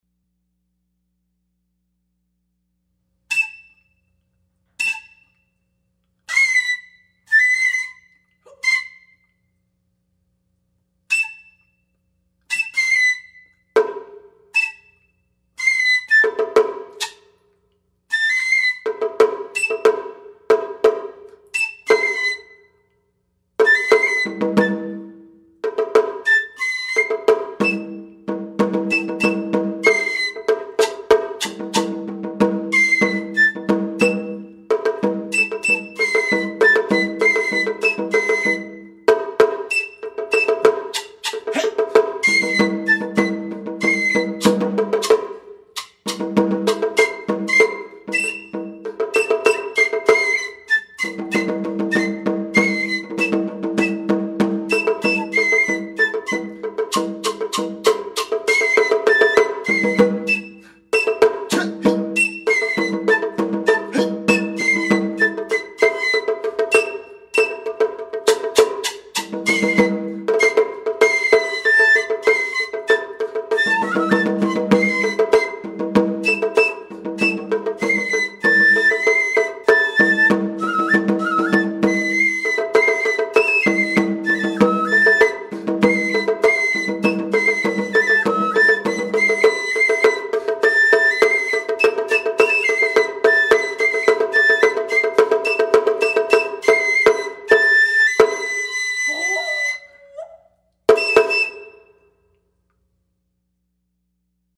taiko, percussion, alto saxophone, fue, voice